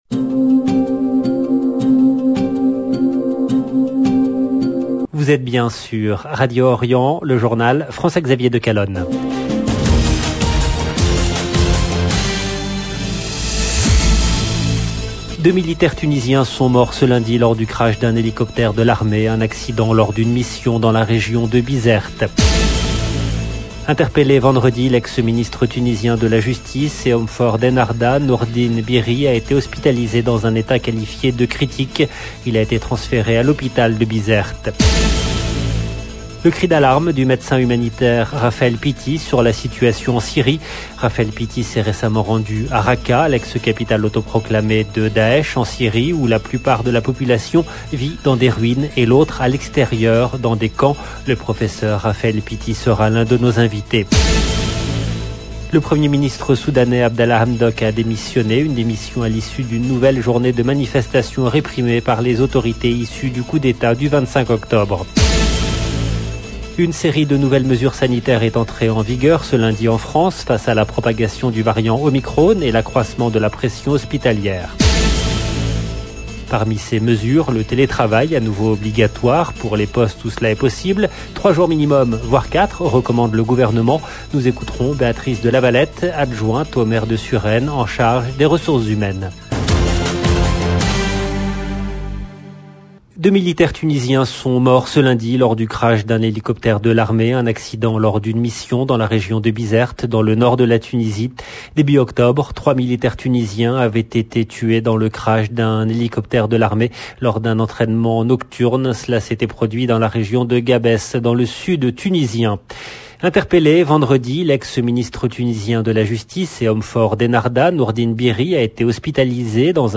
LB JOURNAL EN LANGUE FRANÇAISE
Nous écouterons Béatrice de Lavalette, adjointe au maire de Suresnes, en charge des Ressources Humaines. 0:00 16 min 57 sec